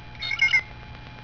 Bluejay2
BLUEJAY2.wav